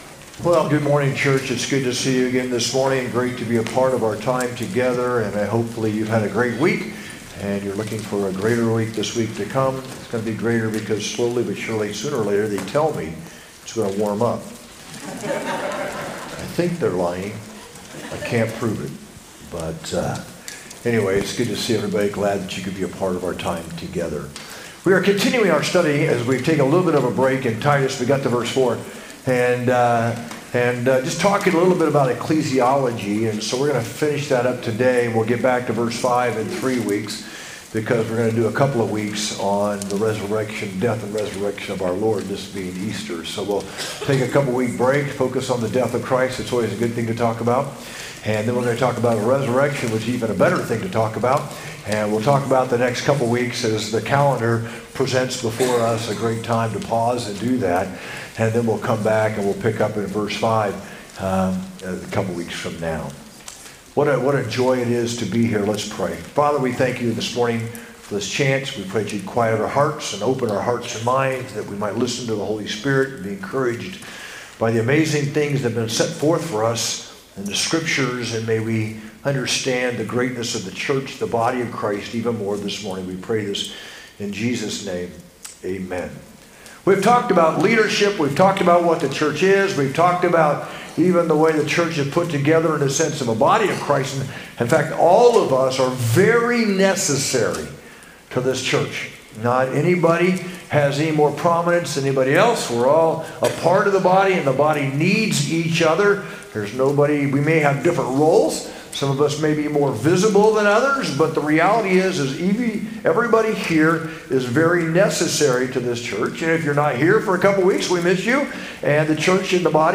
sermon-4-6-25.mp3